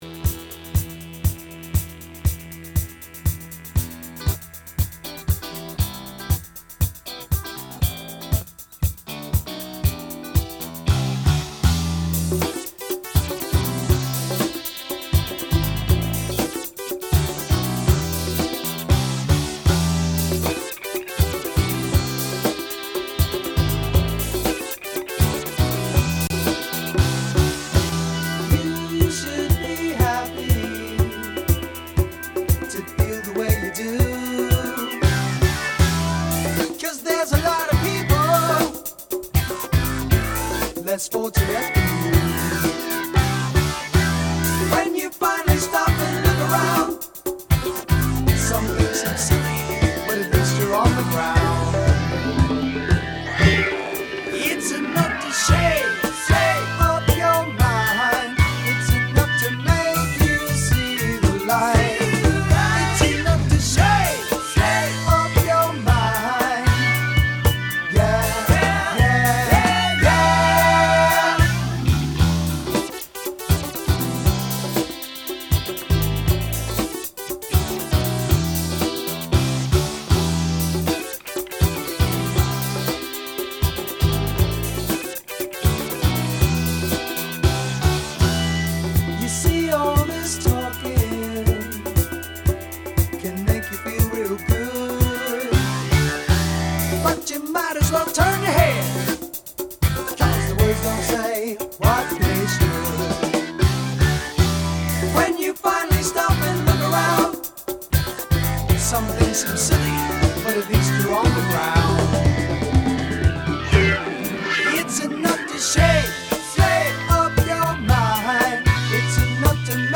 Unreleased demo